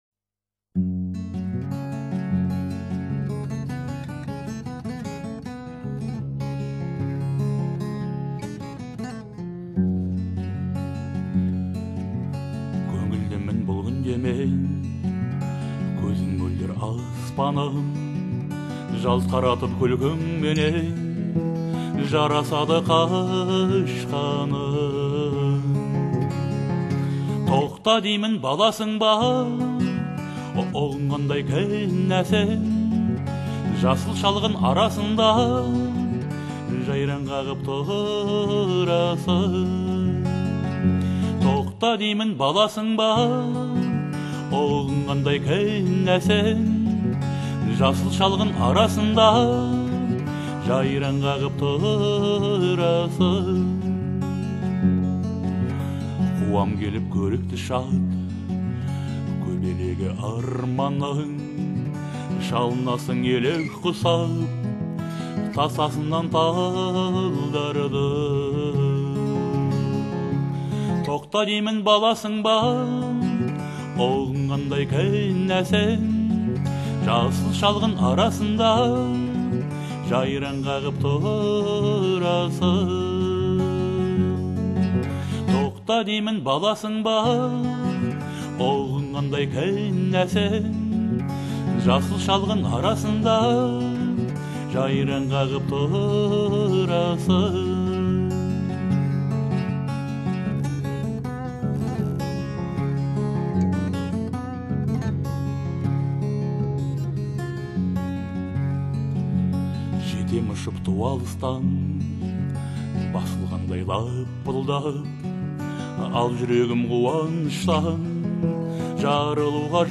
это яркий пример казахского народного жанра